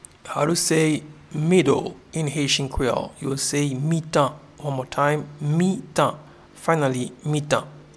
Pronunciation:
Middle-in-Haitian-Creole-Mitan.mp3